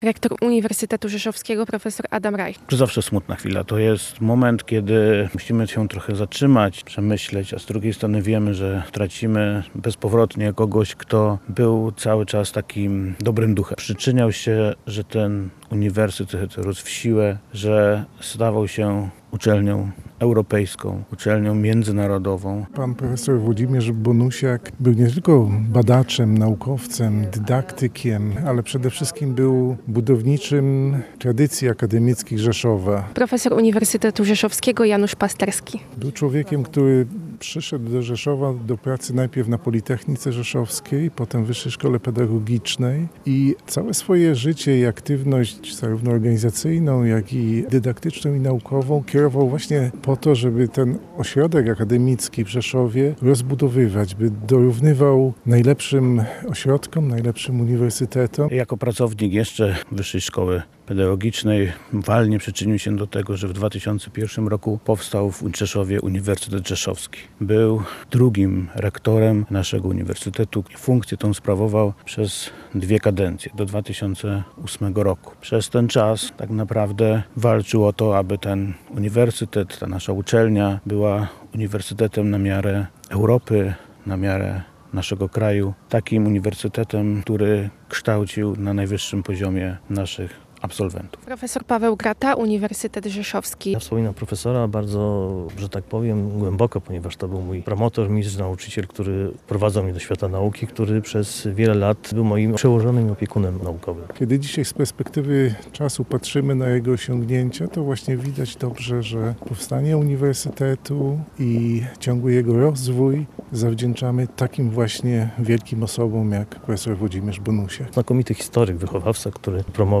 Uroczystości pogrzebowe odbyły się (16.10) na cmentarzu komunalnym Wilkowyja, gdzie żegnano wybitnego historyka i pedagoga. Relacja